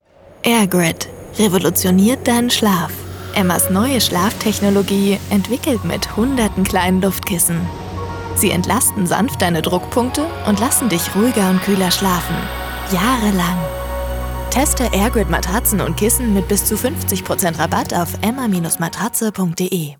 hell, fein, zart
Jung (18-30)
Eigene Sprecherkabine
Off, Commercial (Werbung)